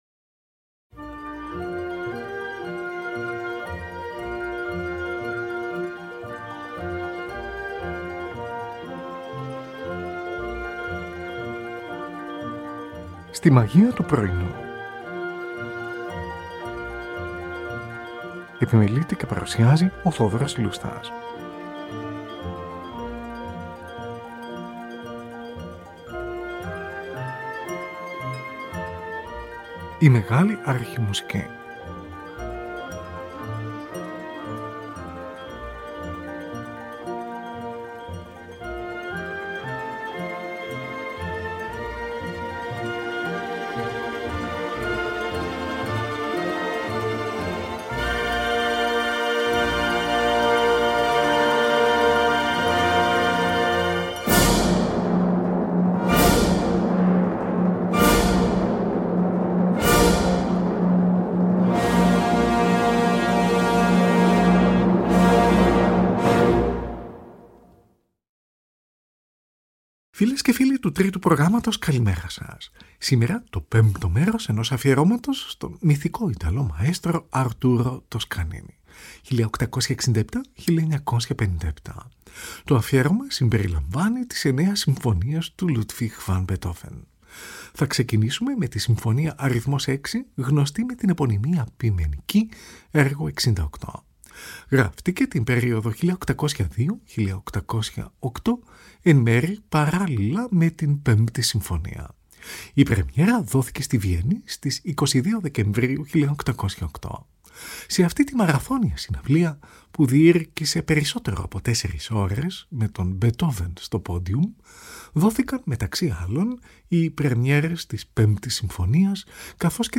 Tη Συμφωνική του NBC διευθύνει ο Arturo Toscanini . Zωντανή ραδιοφωνική μετάδοση στις 11 Νοεμβρίου 1939 .
Ορχηστρικη Εισαγωγη